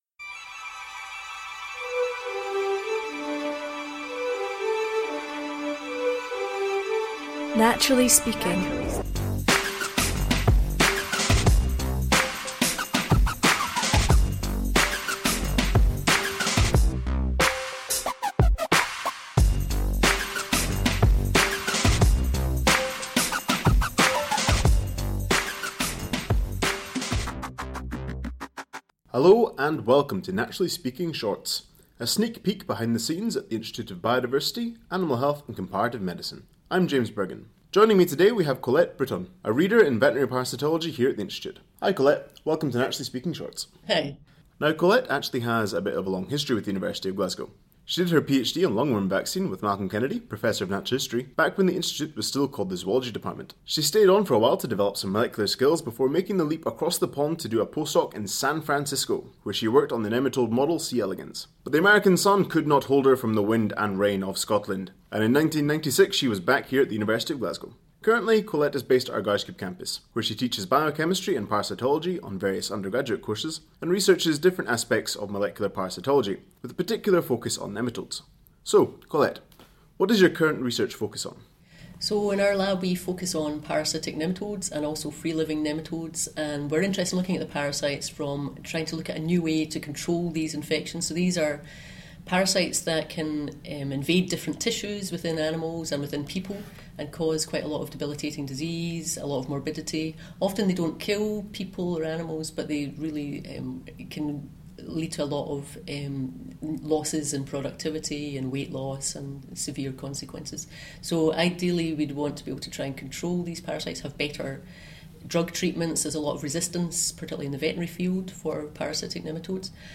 Episode 28 – At war with worms: an interview